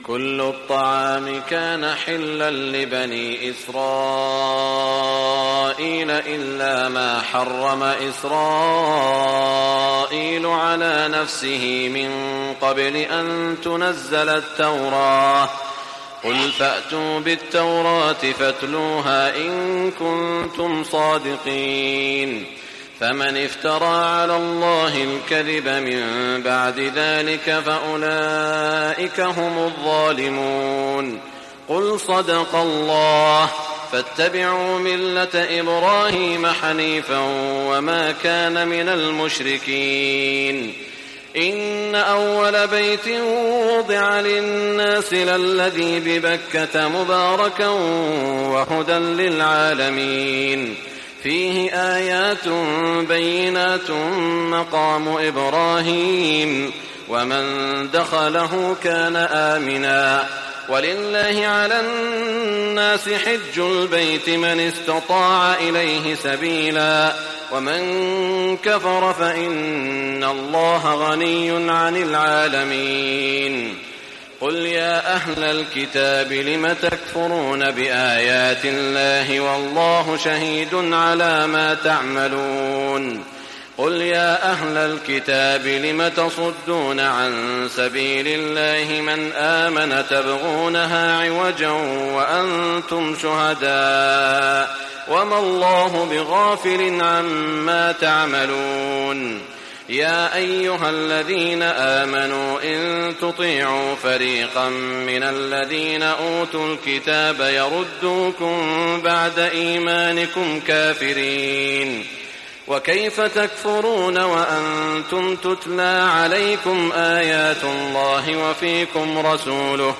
تهجد ليلة 24 رمضان 1420هـ من سورة آل عمران (93-185) Tahajjud 24 st night Ramadan 1420H from Surah Aal-i-Imraan > تراويح الحرم المكي عام 1420 🕋 > التراويح - تلاوات الحرمين